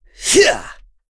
Kasel-Vox_Attack1.wav